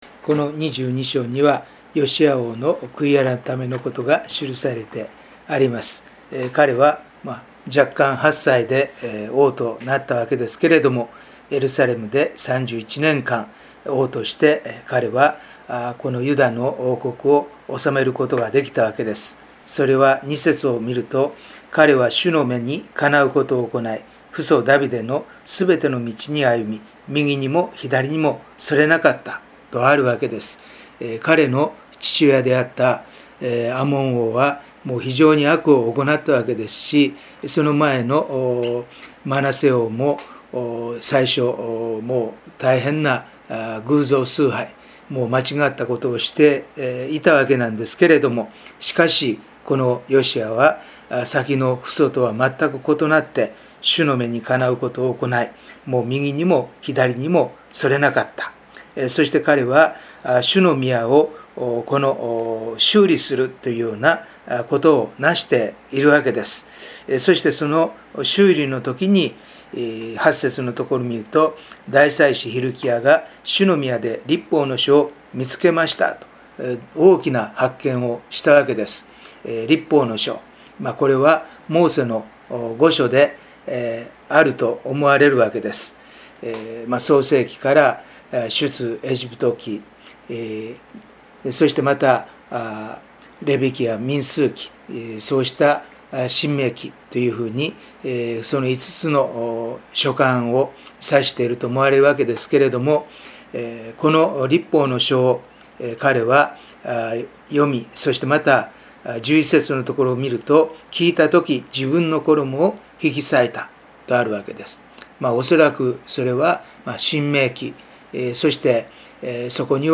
8月のデボーションメッセージ